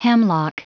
Prononciation du mot hemlock en anglais (fichier audio)
Prononciation du mot : hemlock